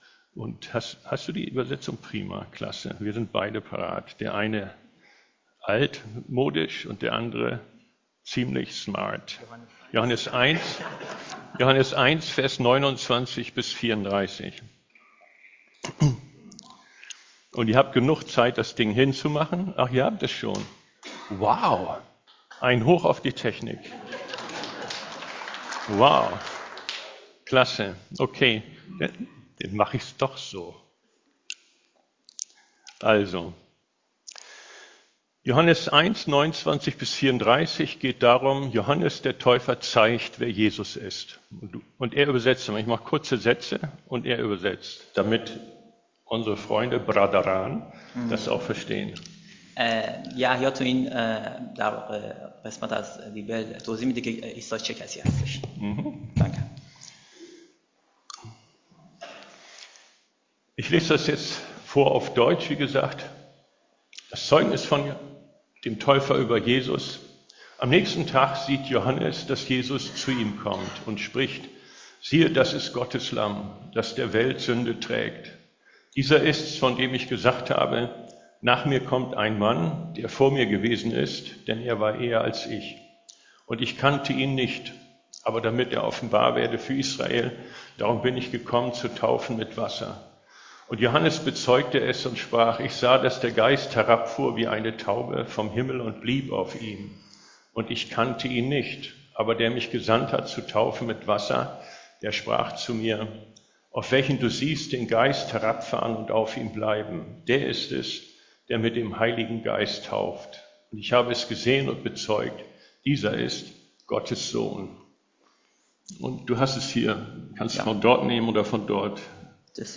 Datum 13.10.2024 Thema Taufgottesdienst - Johannes der Täufer zeigt, wer Jesus ist.